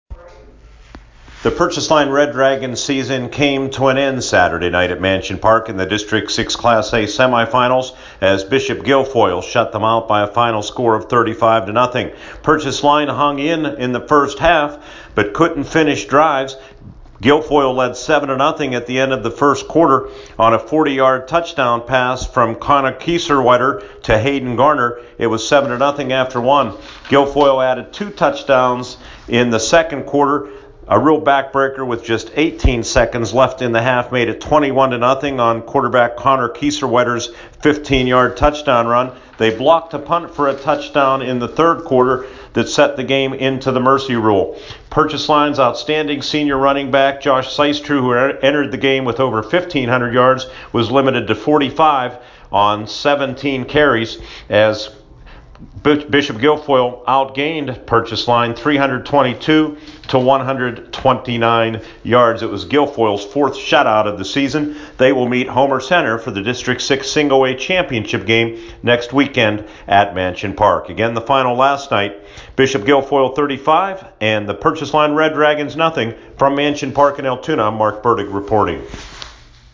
a recap on U92.5 FM